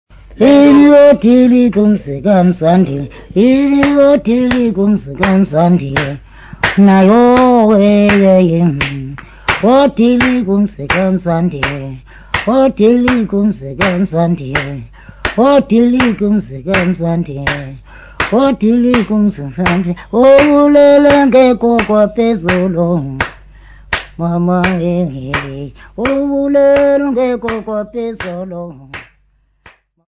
Folk music
Field recordings
sound recording-musical
Traditional Xhosa Song with Uhadi bow accompaniment